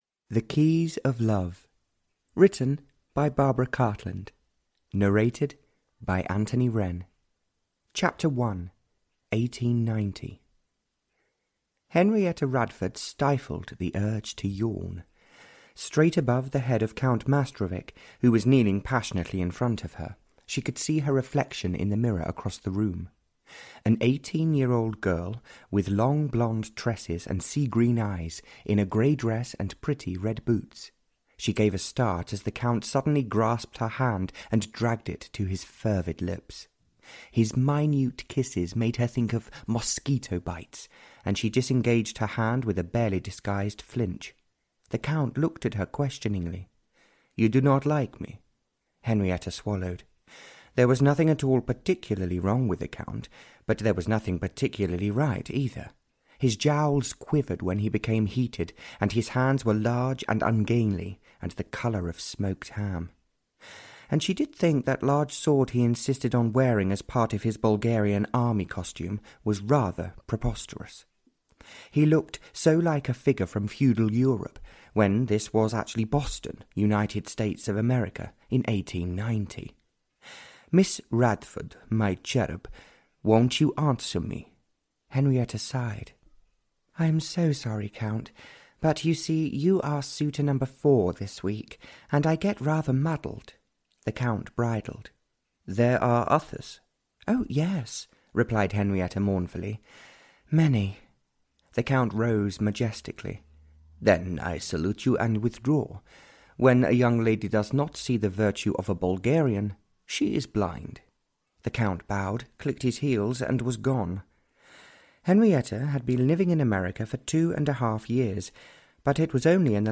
Audio knihaThe Keys Of Love (Barbara Cartland’s Pink Collection 58) (EN)
Ukázka z knihy